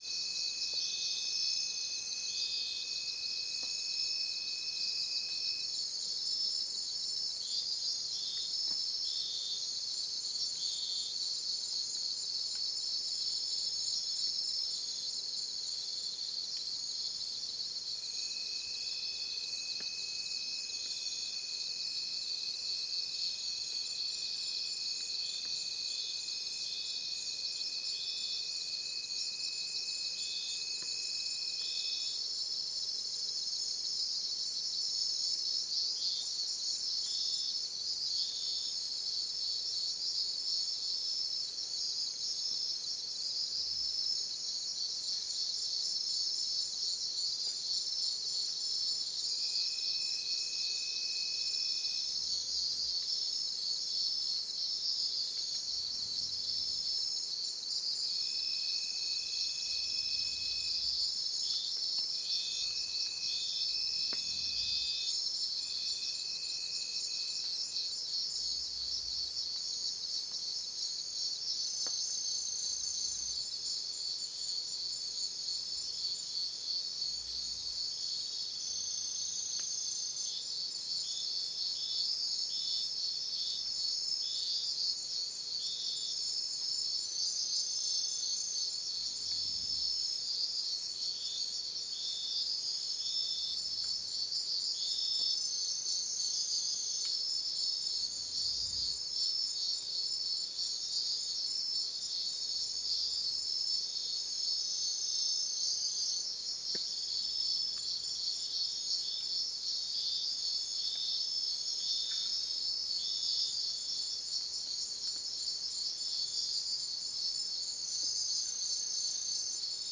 🌲 / foundry13data Data modules soundfxlibrary Nature Loops Forest Night
forest-night-1.mp3